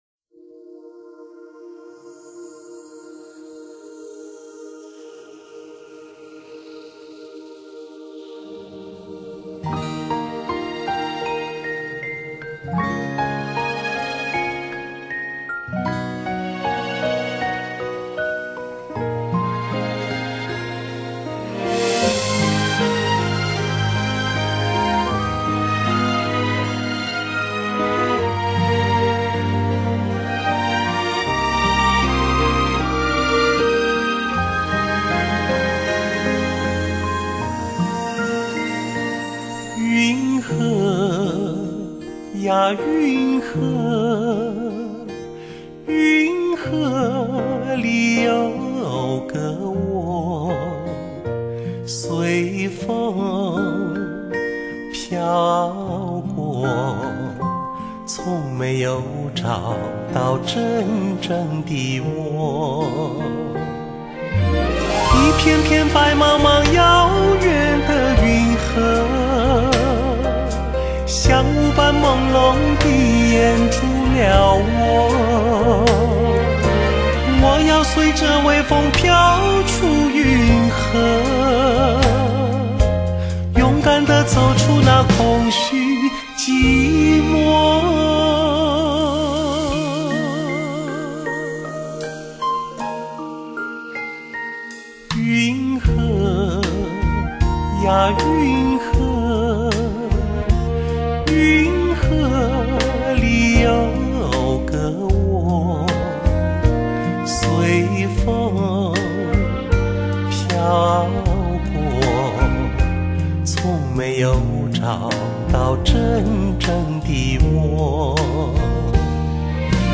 这些都是清新唯美的流行曲，一首首的情歌犹如难忘的私语情话。